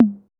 808TOM1 HI.wav